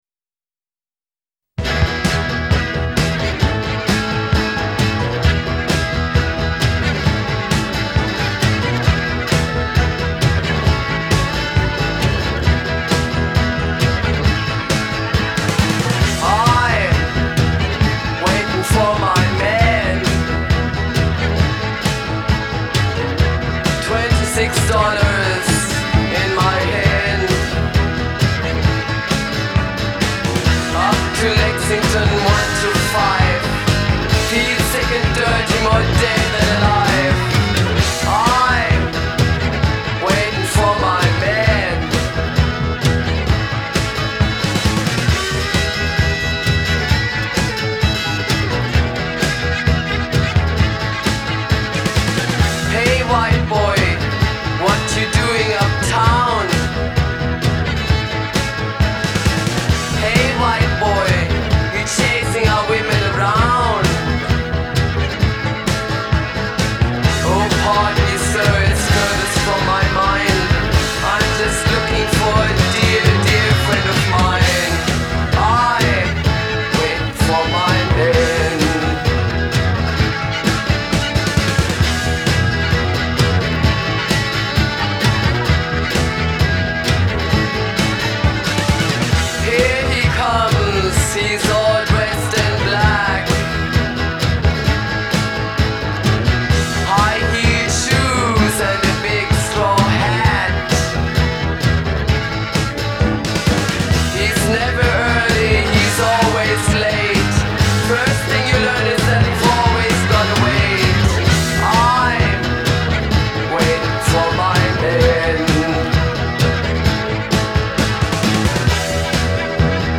Genre : Punk, New Wave